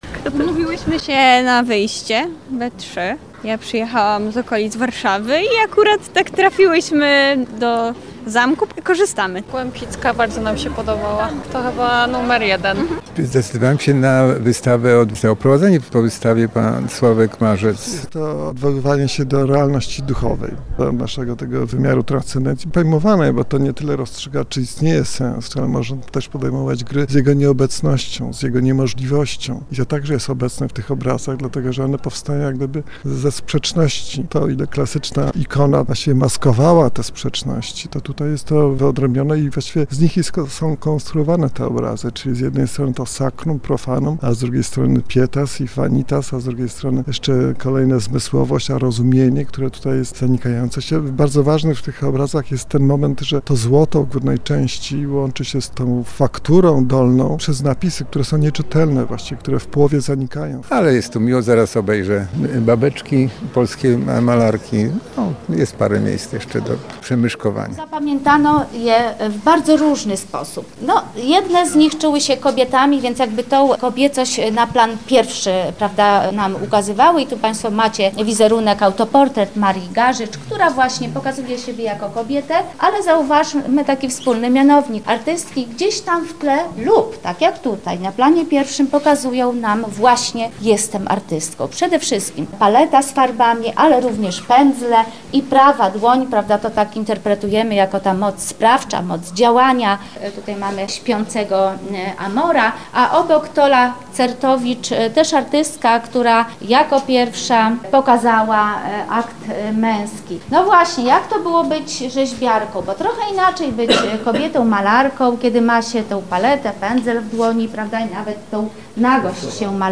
Na relację z tegorocznej Nocy Muzeów